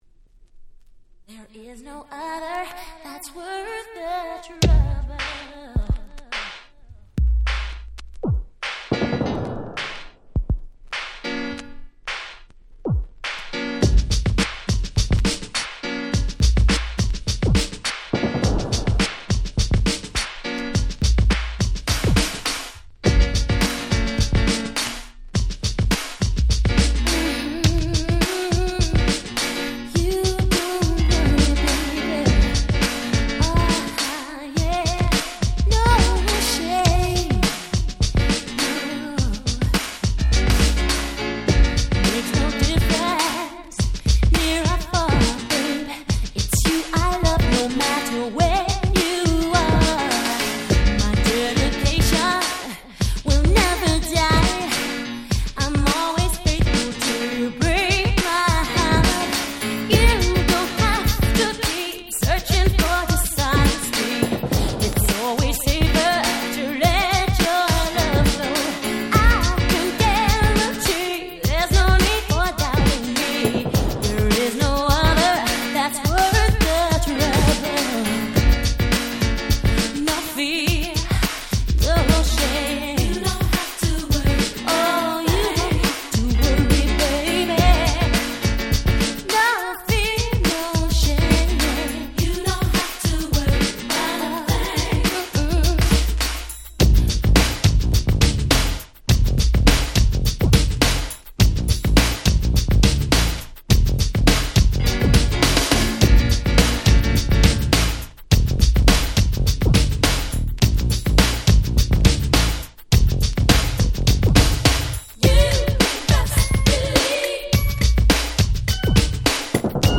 90' Nice R&B !!
タイプのキャッチーな1曲。